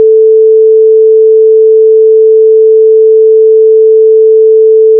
note_440Hz.wav